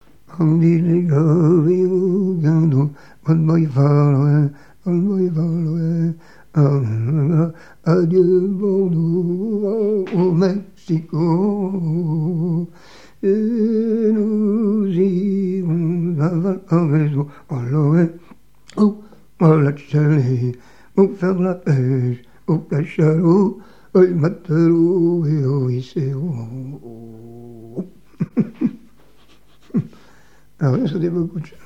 Genre laisse
témoignages et chansons maritimes
Pièce musicale inédite